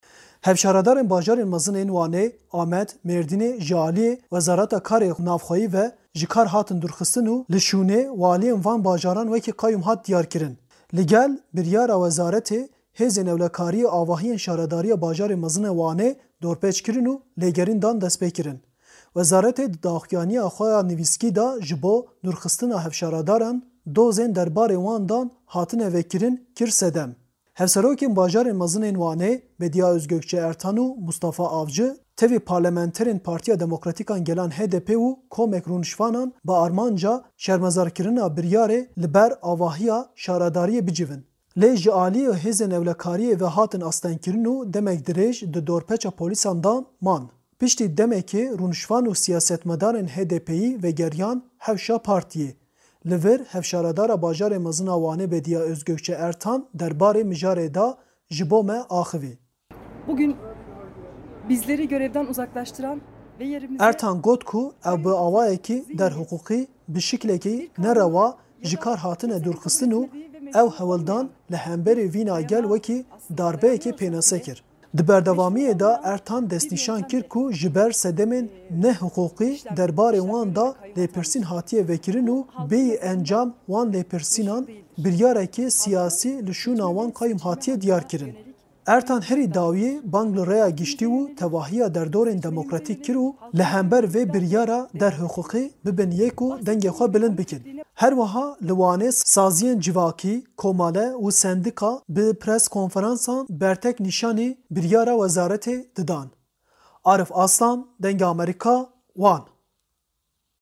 Piştî van geşedanan Hevşaredara Bajarê Mezin a Wanê Bedîa Ozgokçe Ertan derbarê mijarê de axivî: